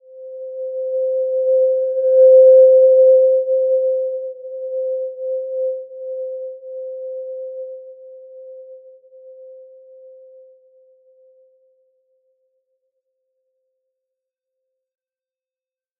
Simple-Glow-C5-f.wav